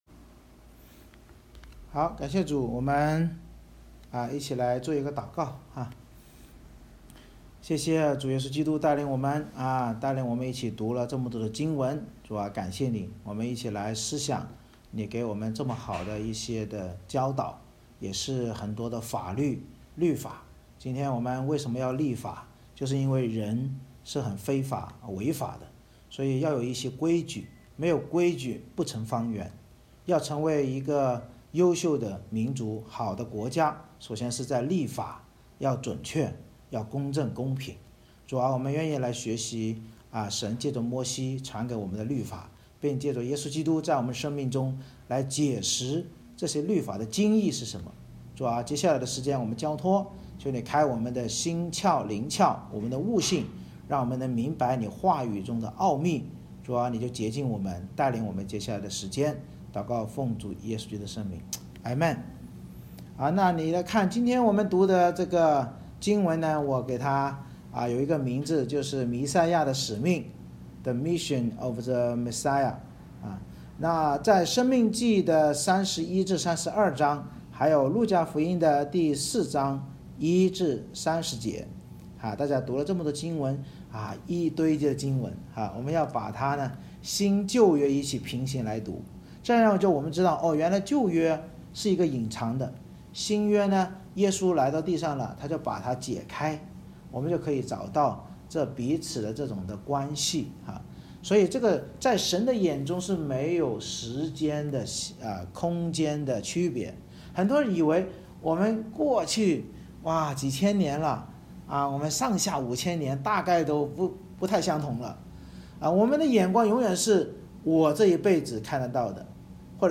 每次崇拜以经文诗歌赞美开始，接着让大人与孩子轮流上台以接力方式读3-4章经文（中/英文）或角色扮演，并简单分享，然后由牧师按新书《圣经导读新唱365》的内容进行归纳解释与应用，最后再唱经文诗歌来回应。